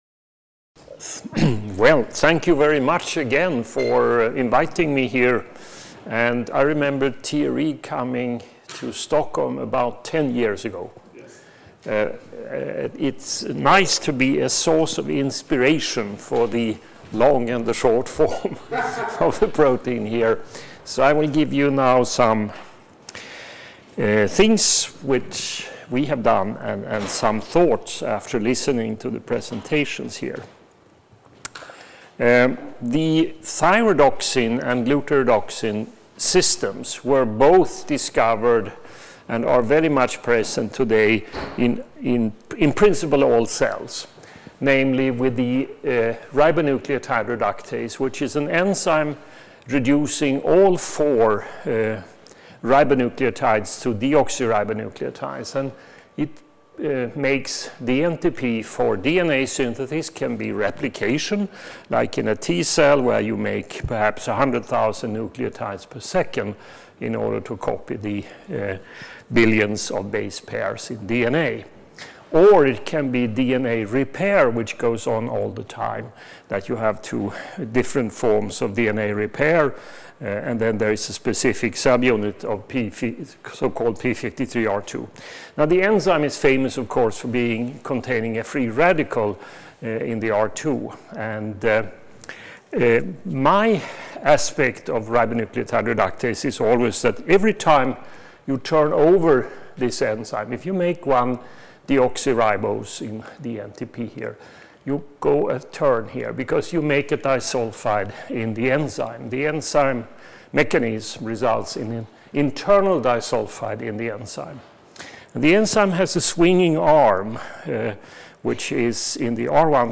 Symposium